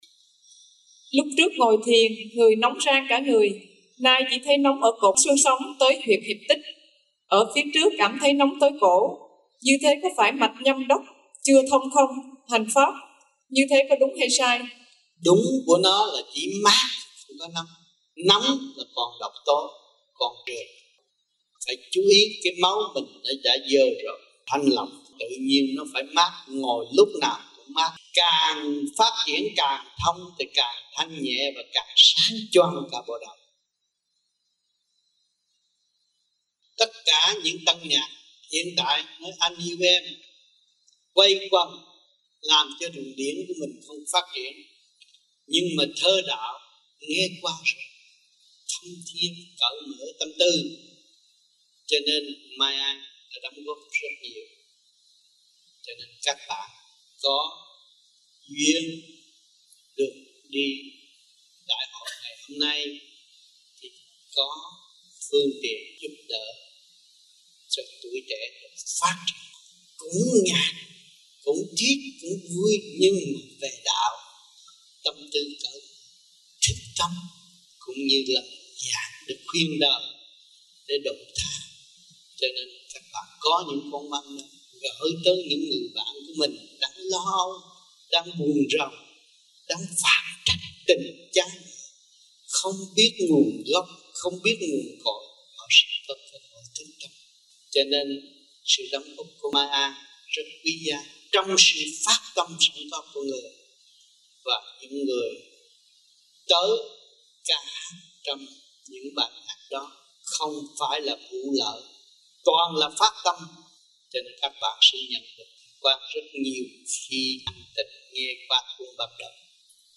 Băng Giảng Và Vấn Đạo Tại Những Đại Hội Vô Vi Quốc Tế